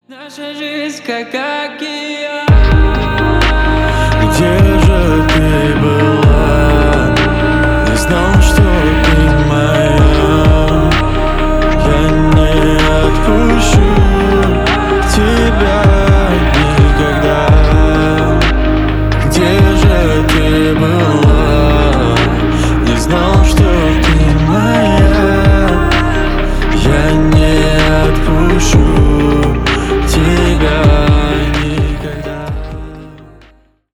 Поп Музыка
спокойные # грустные